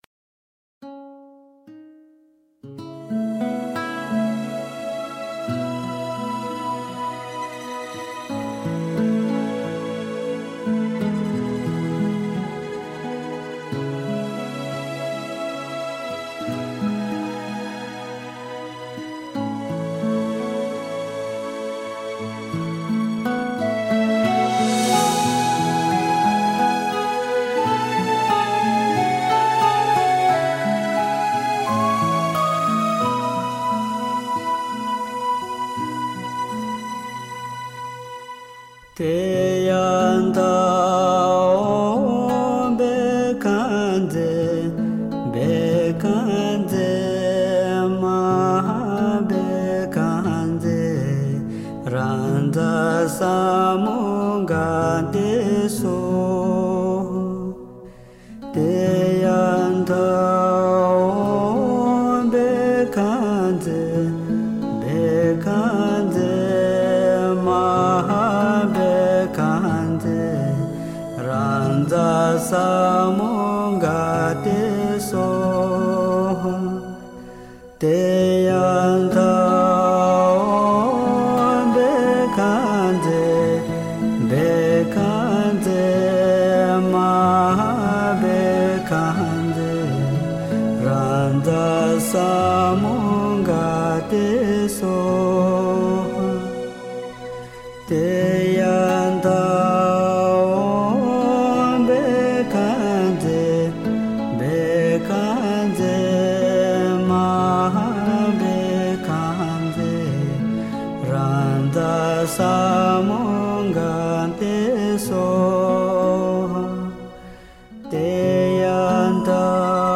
Nhạc chú Dược Sư Tây Tạng